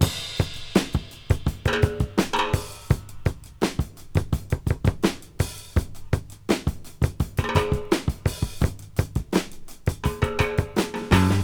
• 84 Bpm Breakbeat E Key.wav
Free drum loop - kick tuned to the E note. Loudest frequency: 1326Hz
84-bpm-breakbeat-e-key-4lE.wav